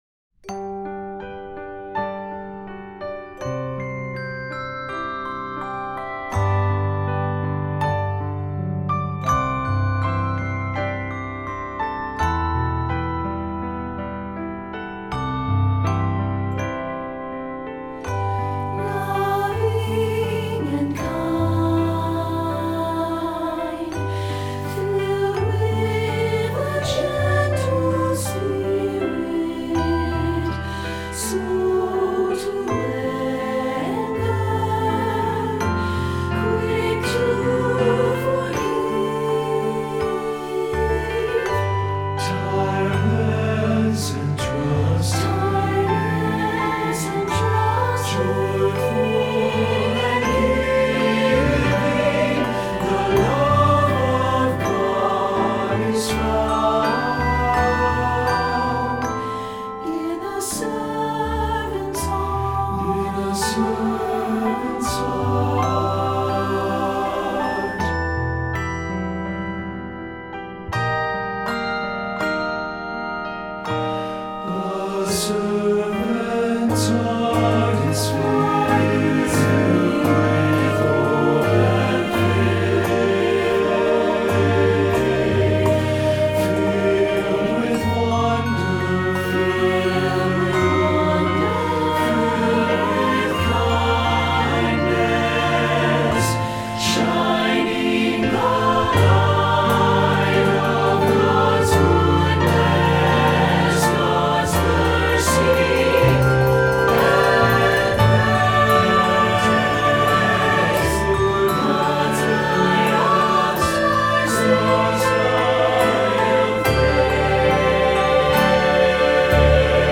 Feuillet pour Chant/vocal/choeur - SATB